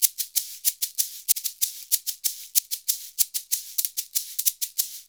Index of /90_sSampleCDs/USB Soundscan vol.56 - Modern Percussion Loops [AKAI] 1CD/Partition B/07-SHAKER094